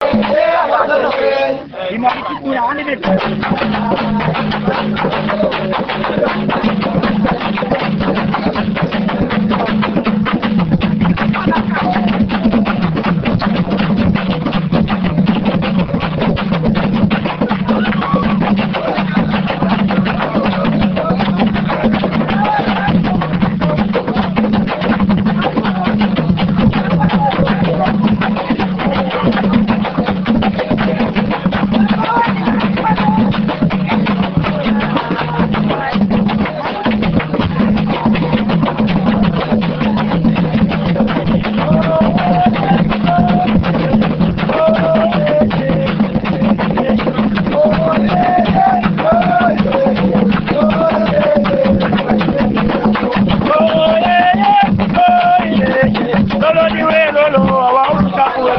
enregistrement durant une levée de deuil (Puubaaka)
danse : songe (aluku)
Genre songe
Pièce musicale inédite